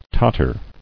[tot·ter]